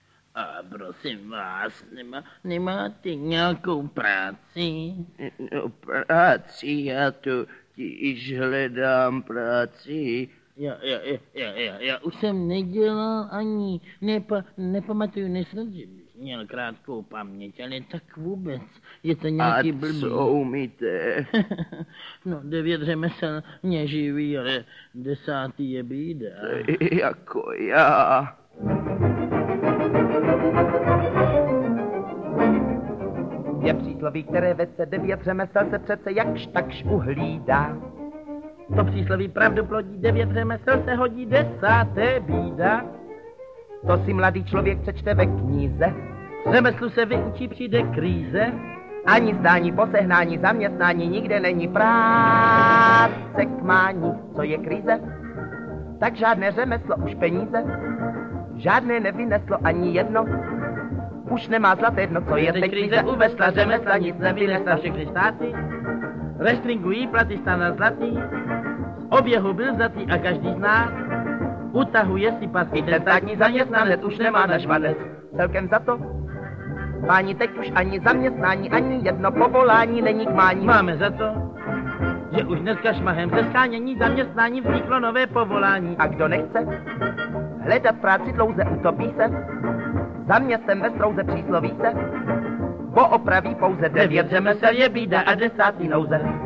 Osvobozené divadlo III. audiokniha
Ukázka z knihy